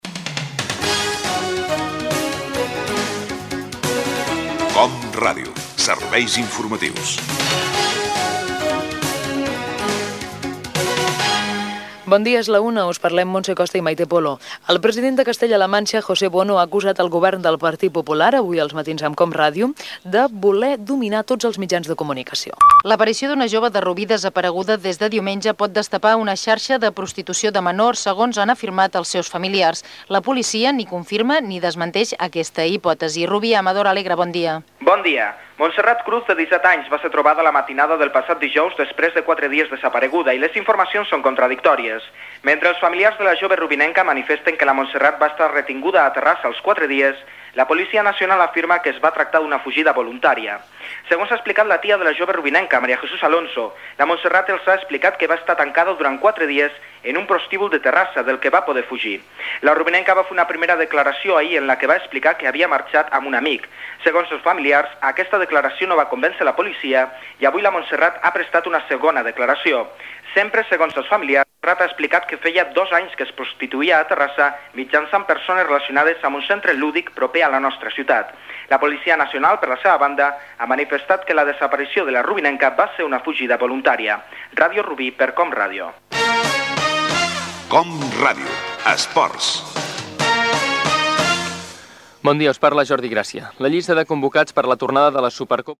Careta, inici butlletí, connexió en directe amb Rubí, separador de la secció d'esports.
Informatiu